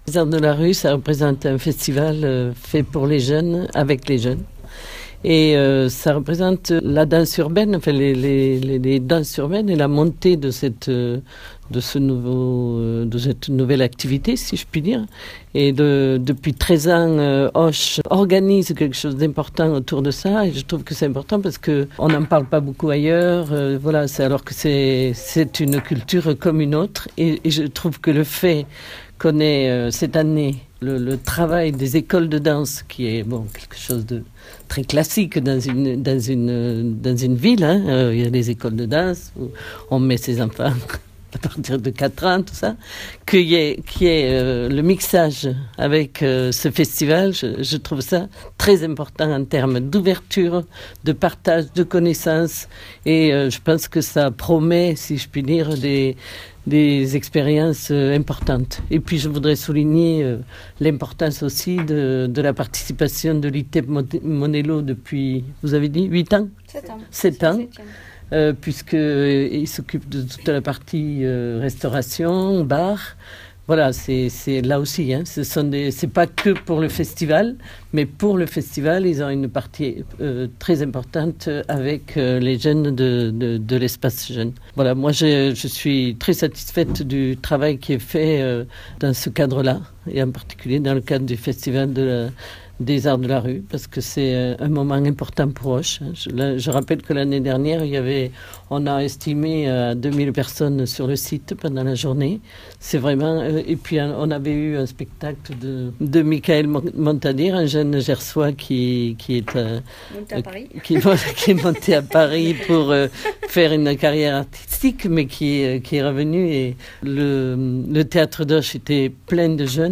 lezarts_de_la_rue2.mp3